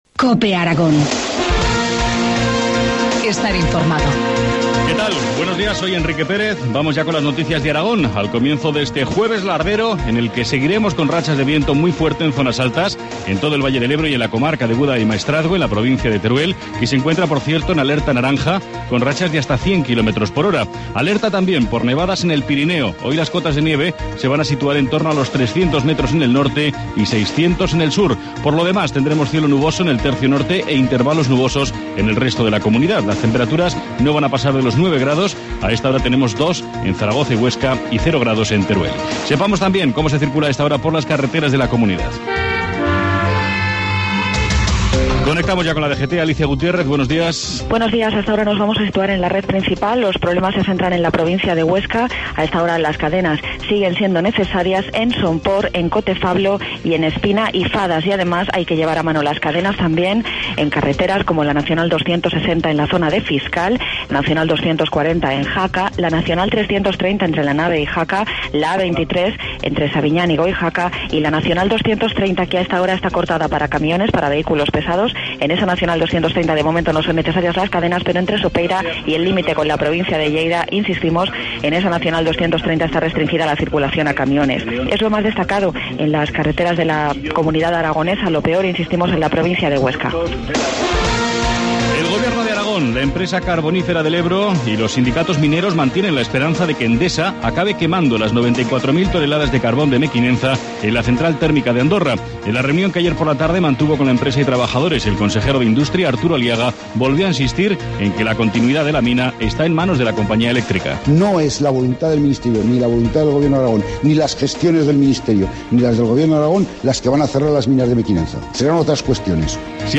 Informativo matinal, jueves 7 de febrero, 7.25 horas